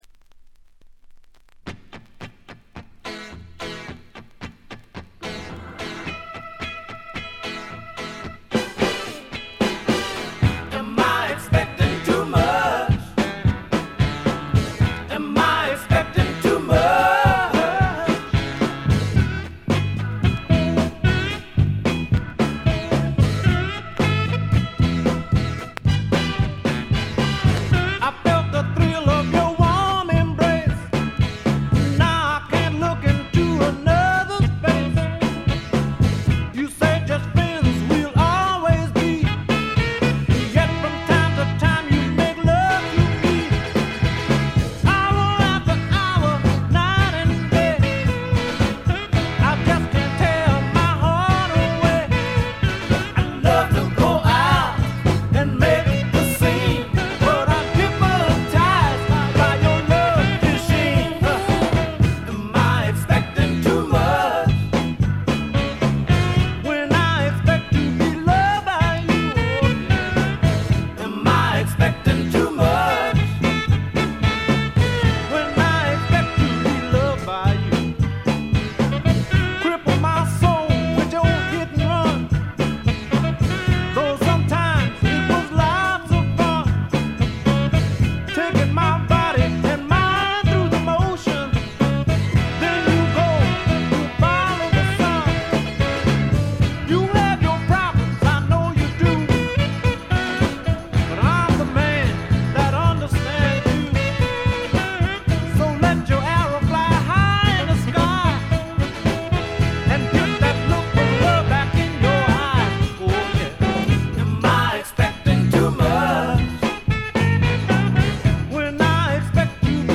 鑑賞を妨げるようなノイズはありません。
セカンドライン・ビート、ニューソウル的なメロウネス、何よりも腰に来るアルバムです。
試聴曲は現品からの取り込み音源です。